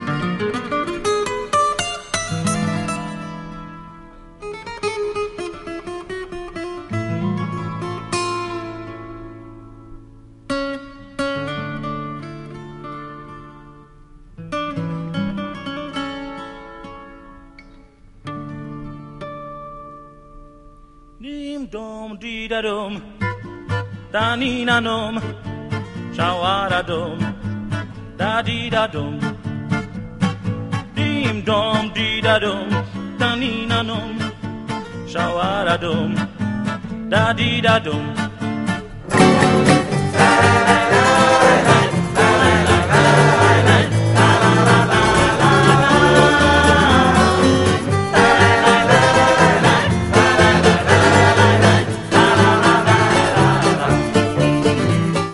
mandoline, background vocal, percussion